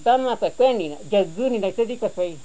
The sample used for this phonetic study was collected in the field during my ethnographic research undertaken in the Middle Caquetá region of the Colombian Amazon between 1994 and 1996.